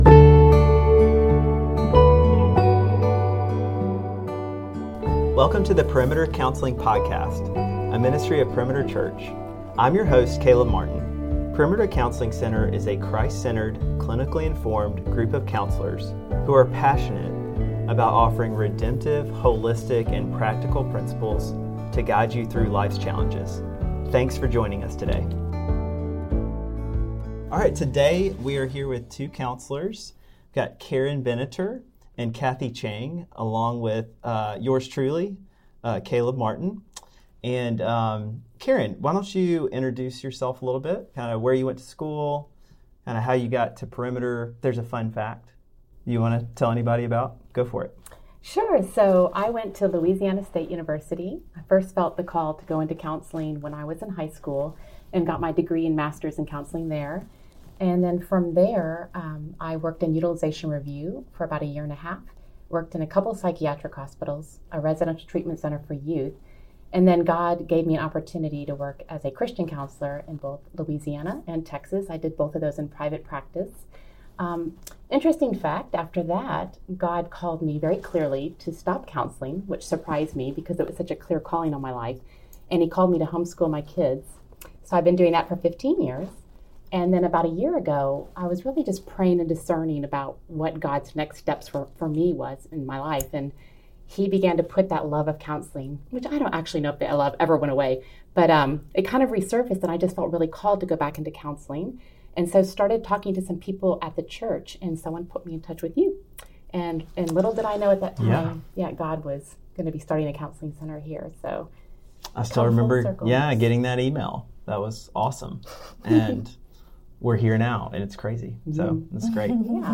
The counselors discuss a different approach, emphasizing that the difficulties we face often stem from within us, not just from external circumstances. They share how we navigate these challenges and the support we receive as believers from the One who created us.